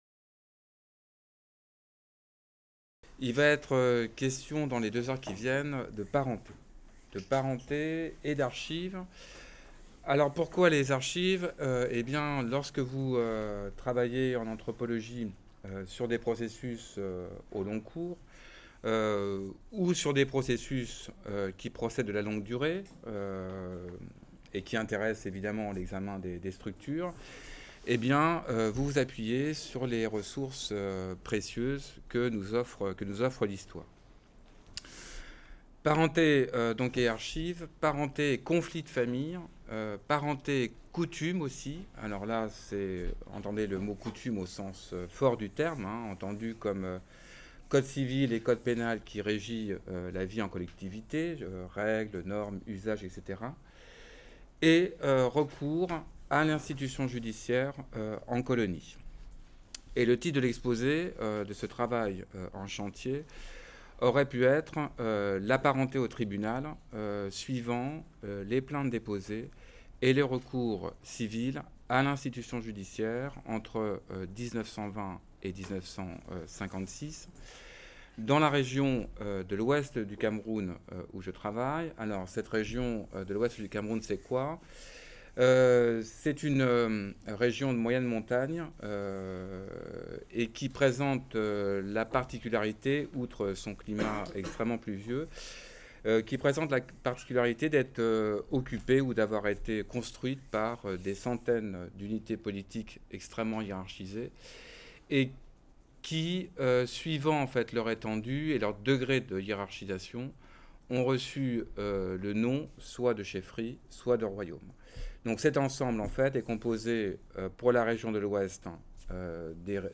Différends familiaux et institution judiciaire au Cameroun (1917-1956)" Présentation au séminaire SéFRA (EHESS) le 25 février 2020.